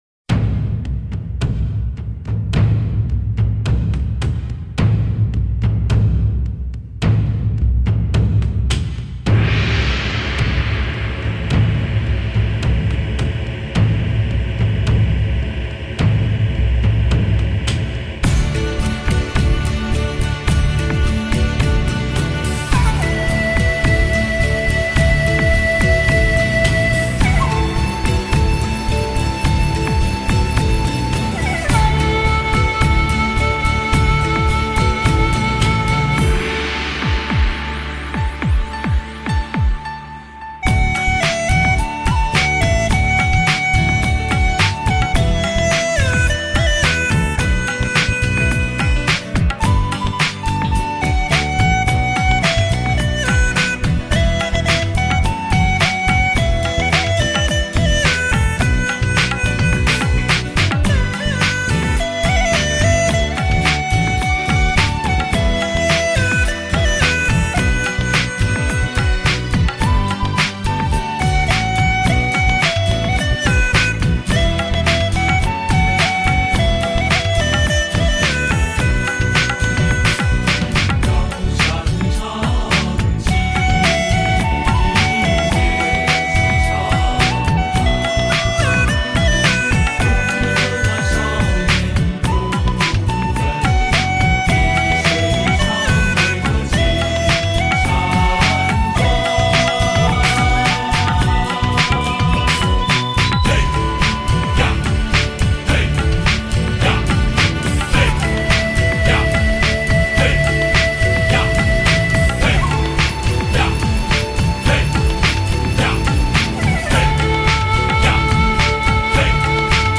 专辑英文名: 葫芦丝演奏
葫芦丝最新现代发烧碟，精彩的现代配器，愿味的民族风格，古典与现代完美的结合，让你感受全新的音乐风情，亲切婉转，温馨感人。
那份具有朦胧色彩的飘逸感，那份深沉醇厚的气韵，使人感到三日绕梁而不绝于耳……
优美葫芦丝 韵味浓郁 收藏极品
秀丽而不浓妆艳抹 活泼而又含蓄深情